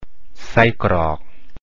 In RTGS: sai krok